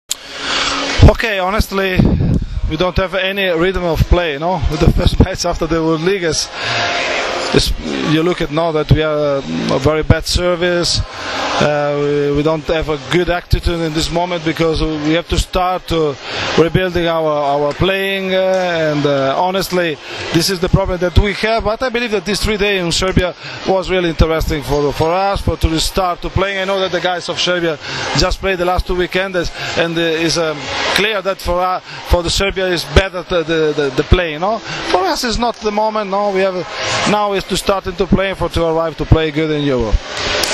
IZJAVA ANDREE ANASTAZIJA